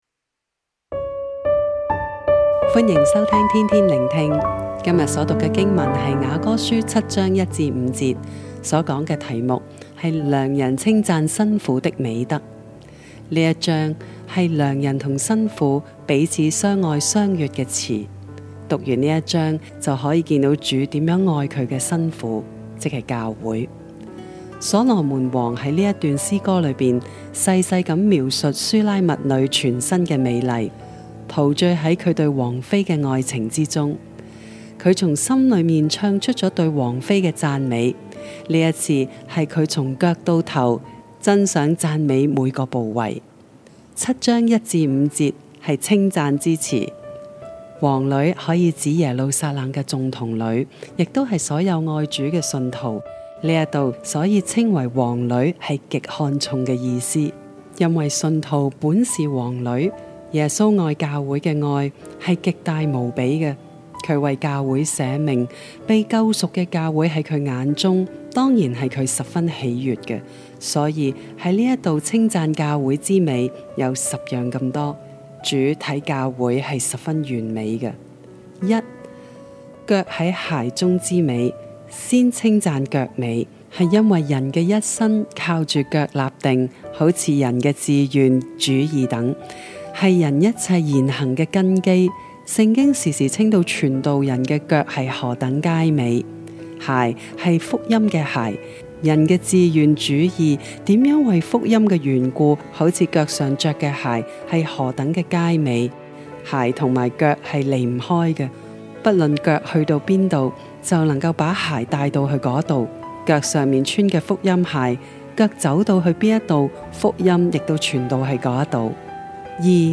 普通话录音连结🔈